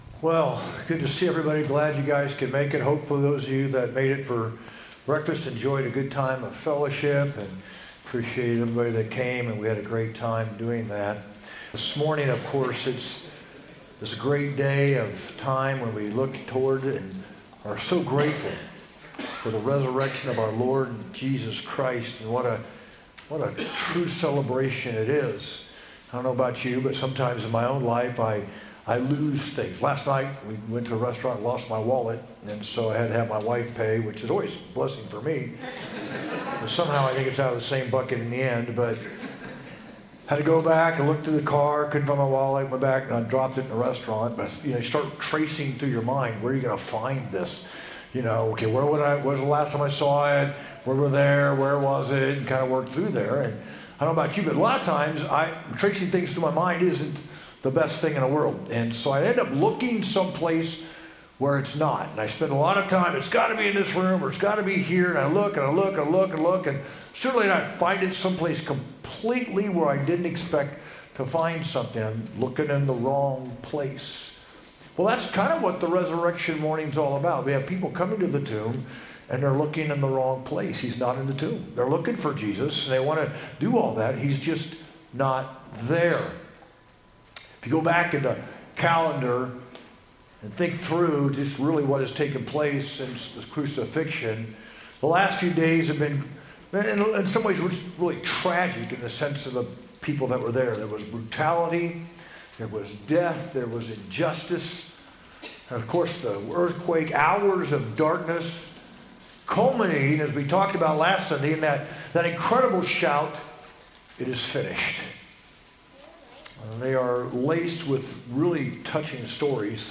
sermon-4-20-25.mp3